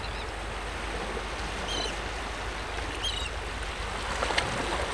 ambsea-mer-vagues-moyennes-et-mouettes-id-0267-ls-edTou2Mf.wav